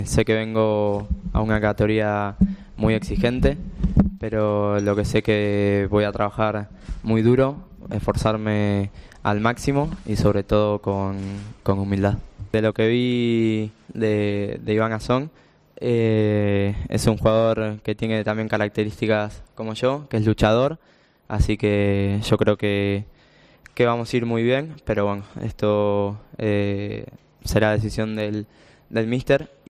Este mediodía ha sido presentado en rueda de prensa Giuliano Simeone, el nuevo delantero del Real Zaragoza, procedente del Atlético de Madrid B